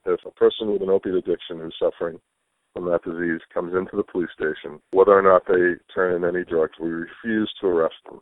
GLOUCESTER POLICE CHIEF LEONARD CAMPANELLO – WHETHER OR NOT THEY TURN IN ANY DRUGS WE REFUSE TO ARREST THEM
CUT-GLAW-ster-POLICE-CHIEF-LEONARD-CAMPENELLO-WHETHER-OR-NOT-THEY-TURN-IN-ANY-DRUGS-WE-REFUSE-TO-ARREST-THEM.mp3